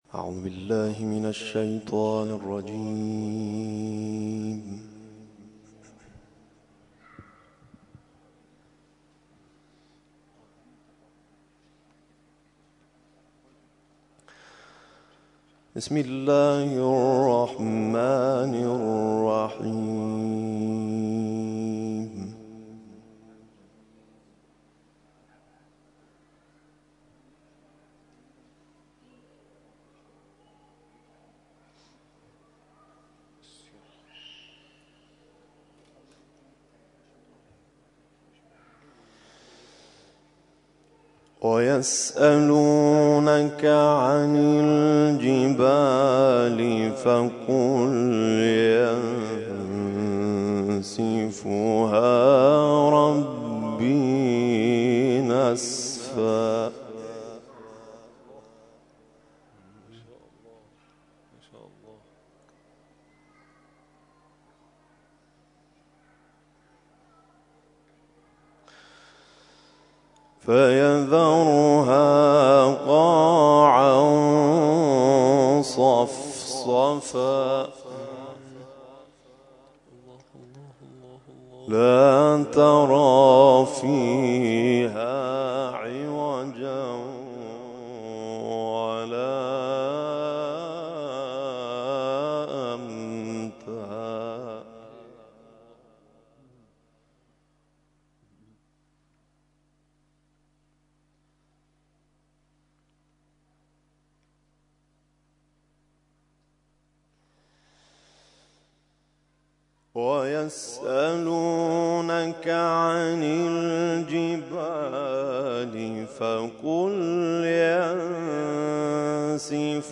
محفل انس با قرآن کریم